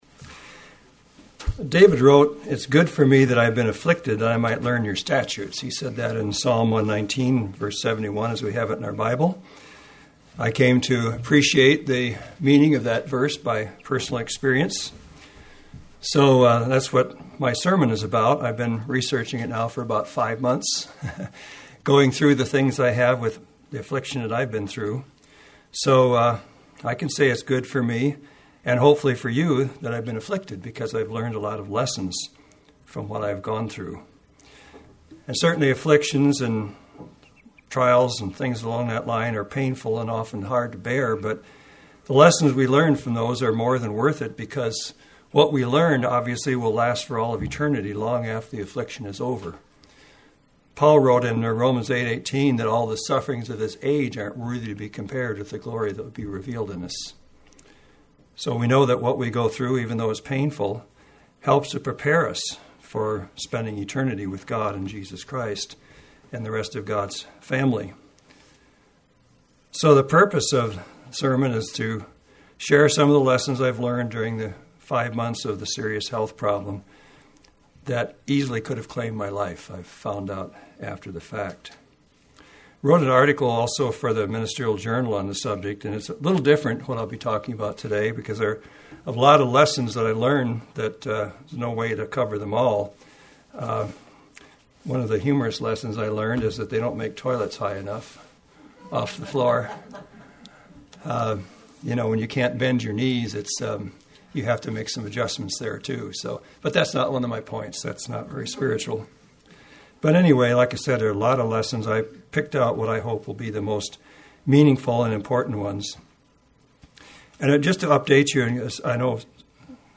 Lessons From a Serious Health Problem Medford – 4-23-11 Bend – 4-30-11